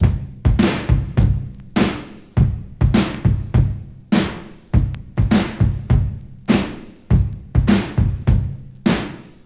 • 93 Bpm Drum Beat E Key.wav
Free drum loop - kick tuned to the E note.
93-bpm-drum-beat-e-key-imR.wav